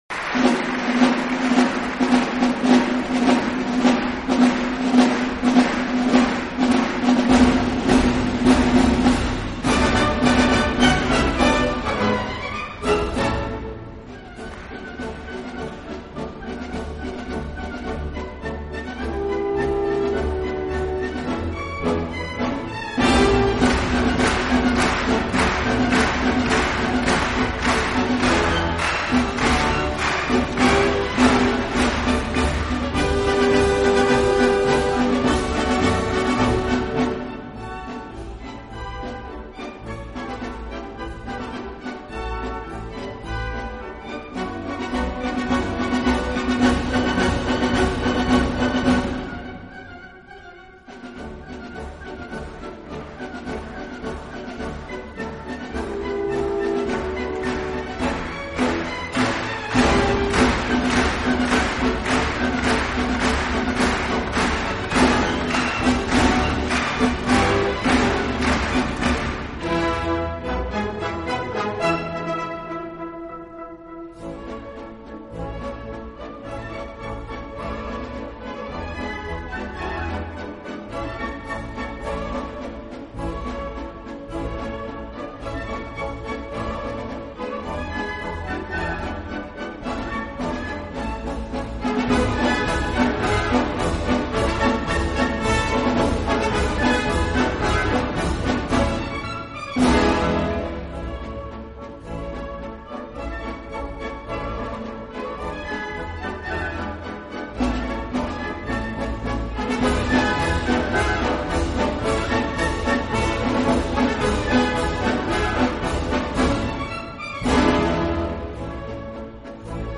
音乐性质：纯音乐